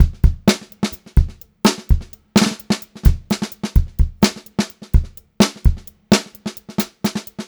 128GRBEAT2-L.wav